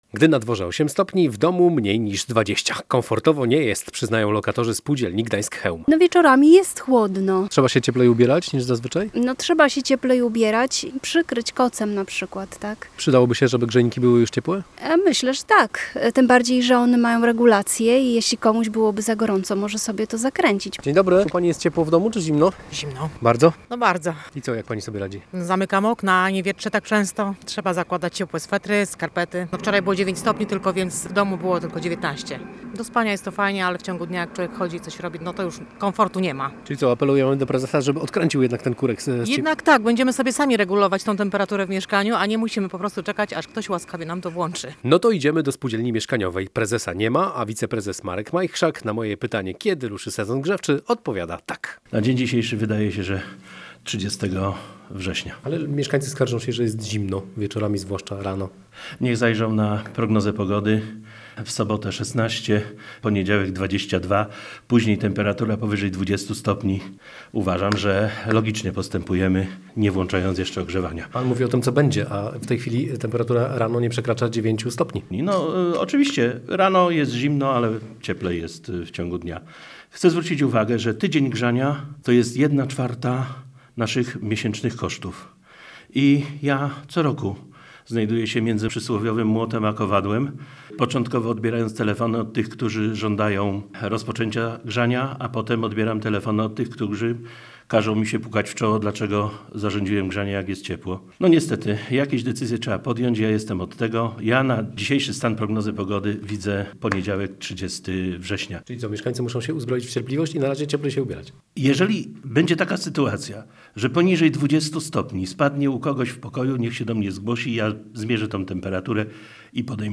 Posłuchaj materiału naszego reportera.